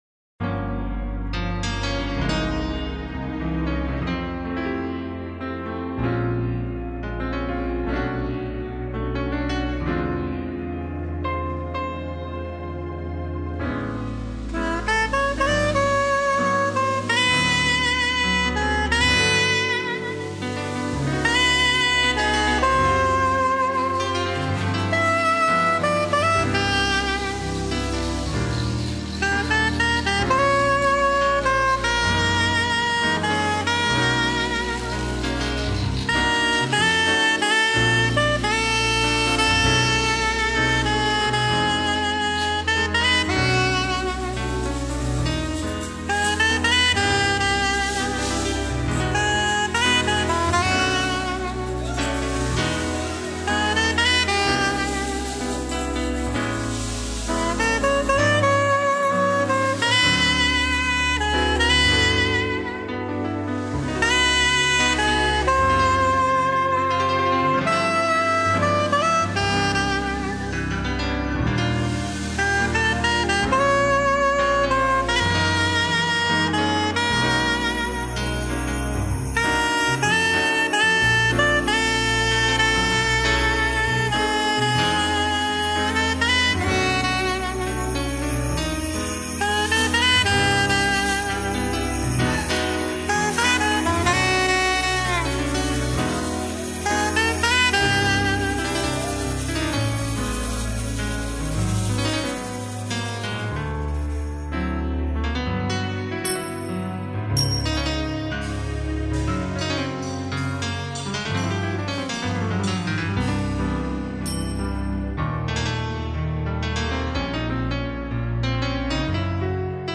1324   04:30:00   Faixa:     Jazz